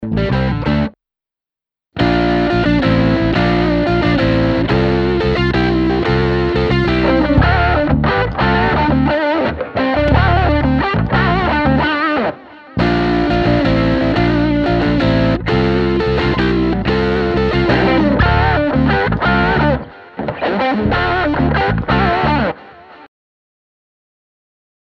I didn't use any widening for the hard left ad right examples, just the same cutoffs.